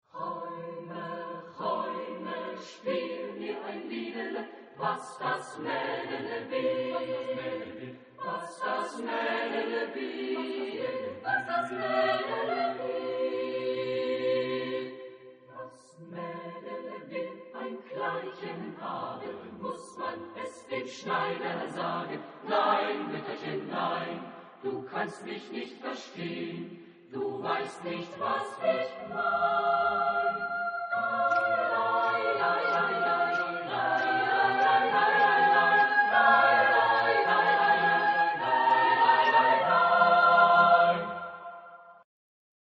Genre-Stil-Form: Volkslied ; Liedsatz ; weltlich
Chorgattung: SSATB  (5-stimmiger gemischter Chor )
Tonart(en): e-moll